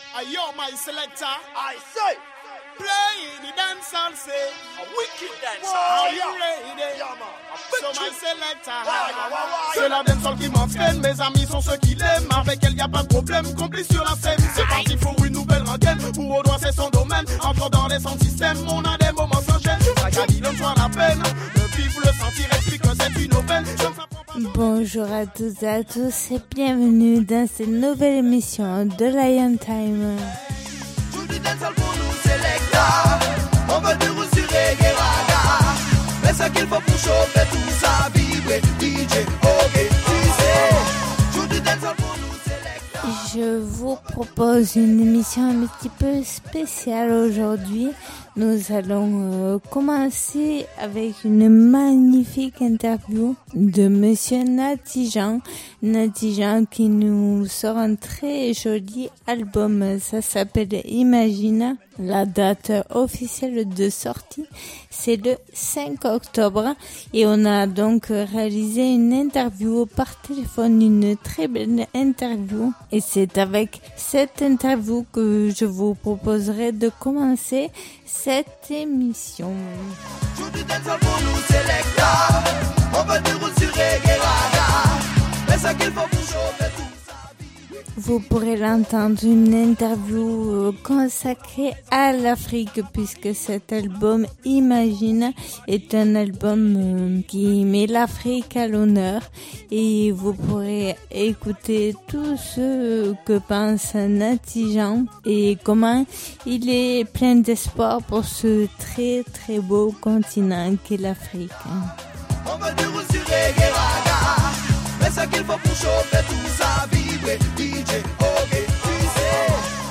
Émission qui invite au voyage et à la découverte du roots, du reggae et du dancehall.